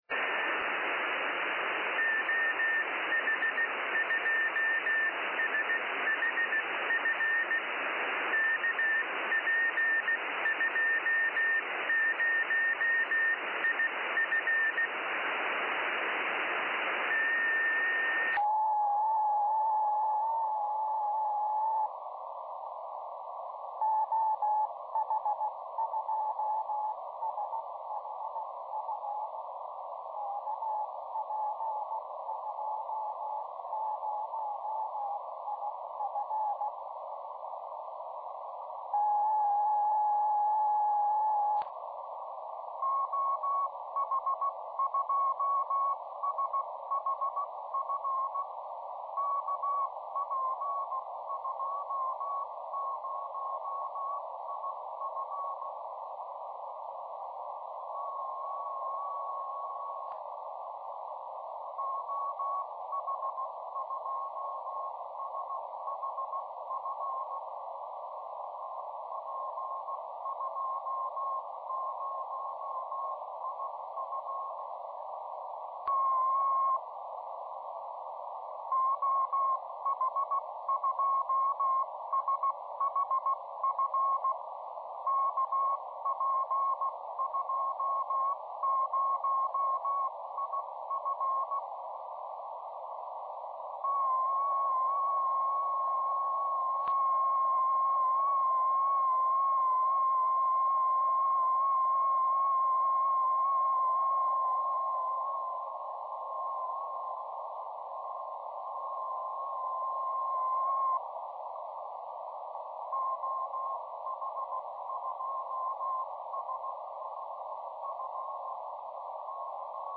słyszał również bikon OH2SHF, niestety brak było stacji z Finlandii.